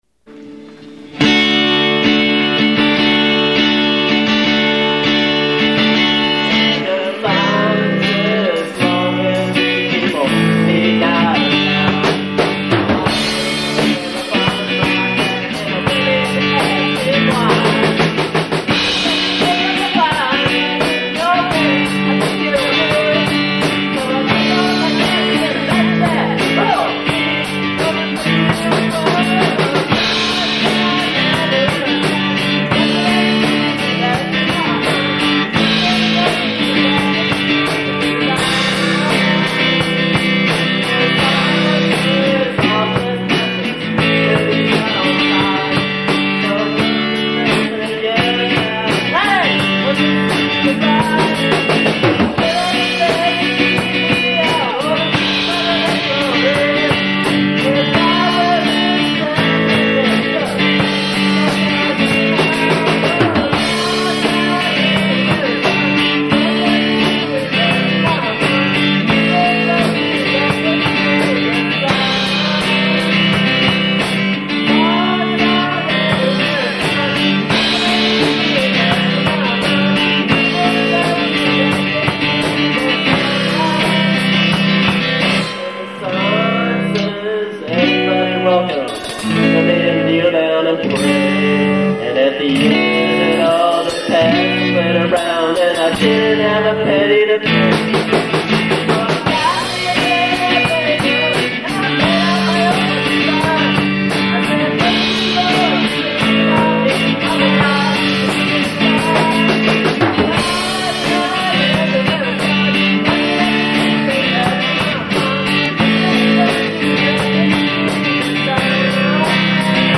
They are completely and unapologetically live.